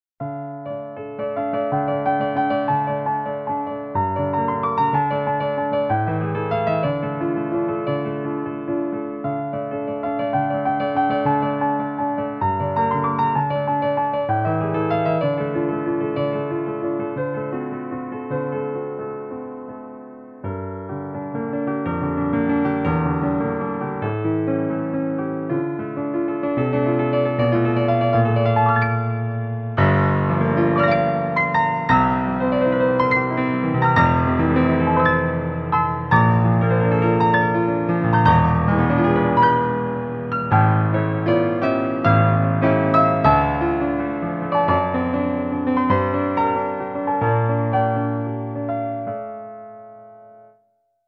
ゲームで使う曲のお試し録音。
汚いし、何がやりたいのか意味不明。